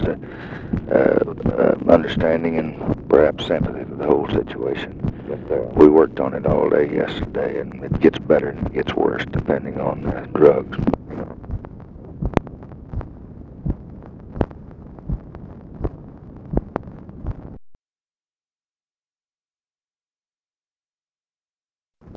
Conversation with (possibly) CARTHA DEKE DELOACH, October 24, 1964
Secret White House Tapes | Lyndon B. Johnson Presidency